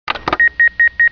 电报机开机.wav